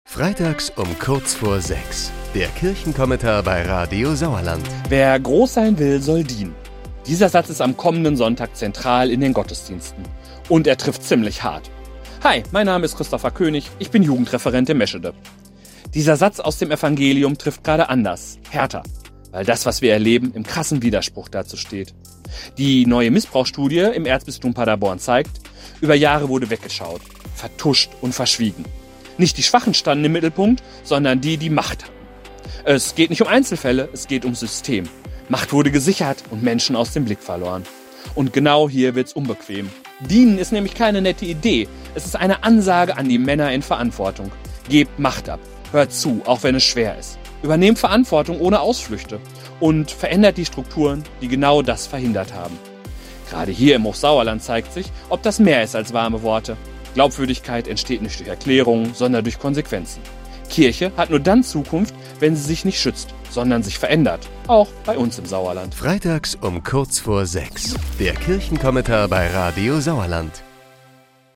Kirchenvertreter greifen aktuelle Themen auf, die uns im Sauerland bewegen.